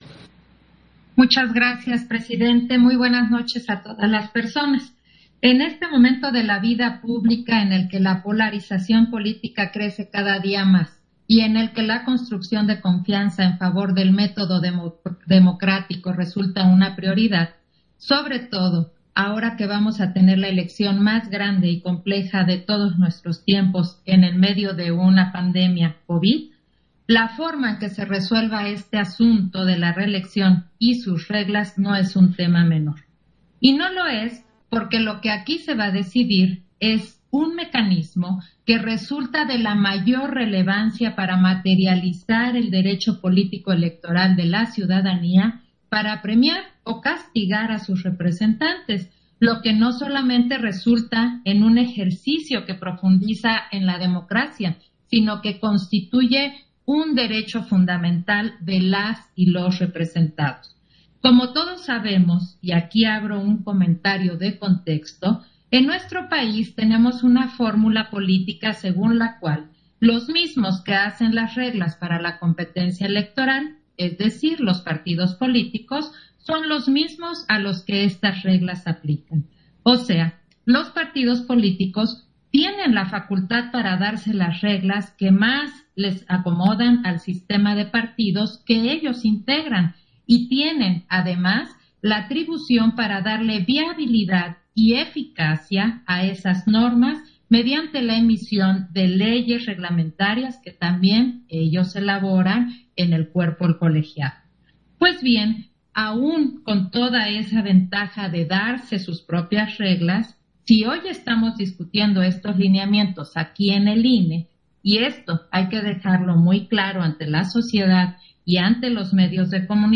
Intervención de Claudia Zavala, en Sesión Extraordinaria, en el punto en que se emiten lineamientos sobre reelección de diputaciones por ambos principios